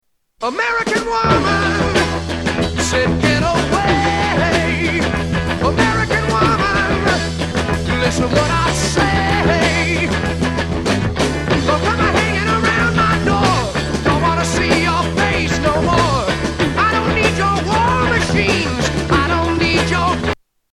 Tags: Sound Effects Rock Truetone Ringtones Music Rock Songs